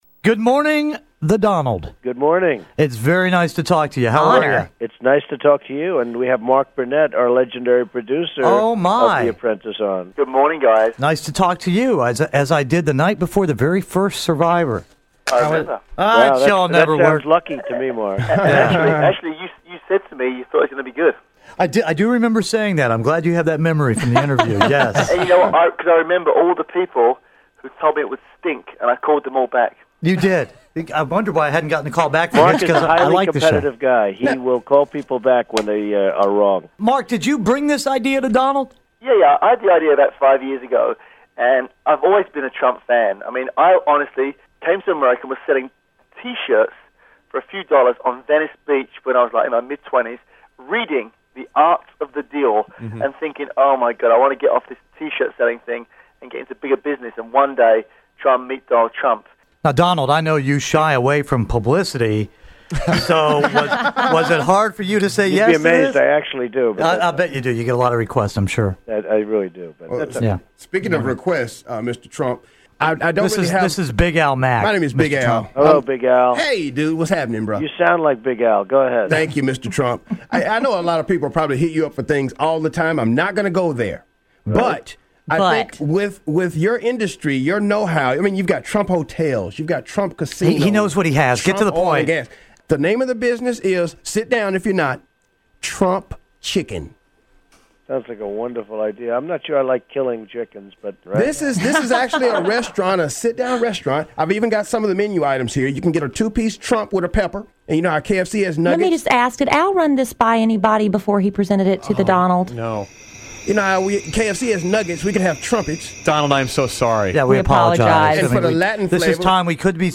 We interviewed Donald Trump in February 2004 about The Apprentice.